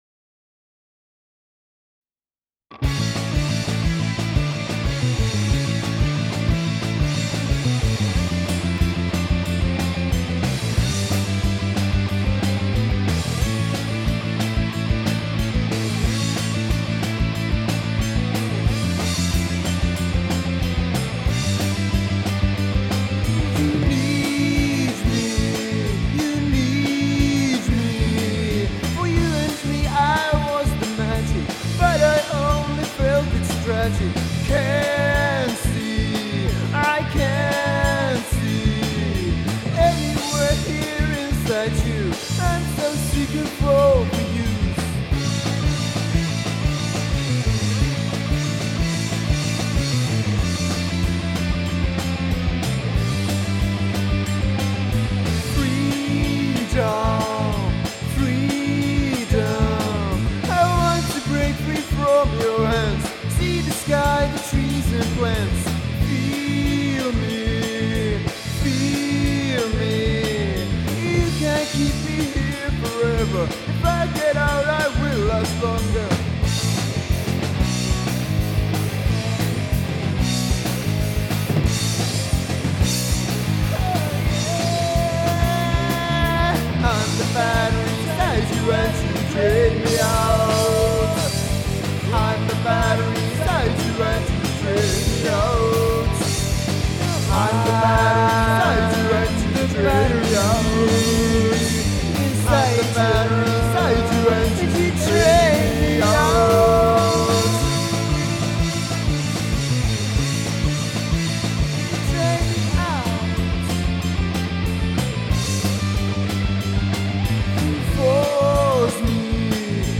Eka demo.